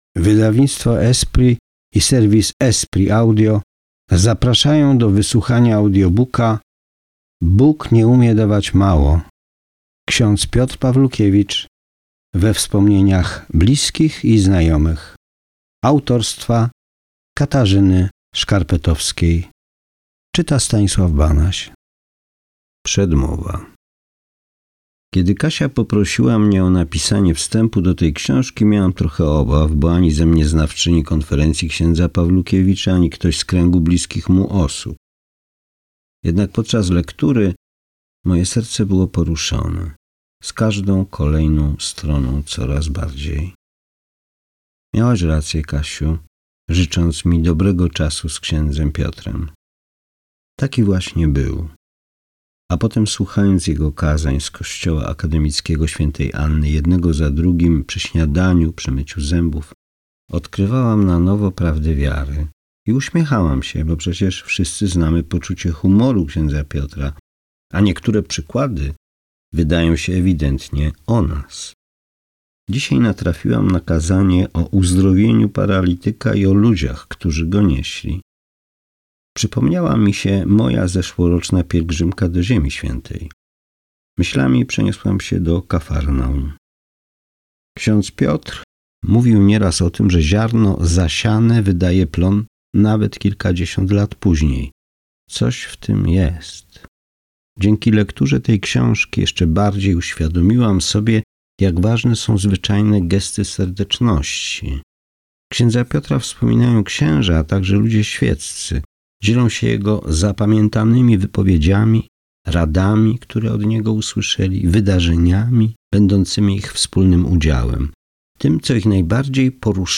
Bóg nie umie dawać mało – audiobook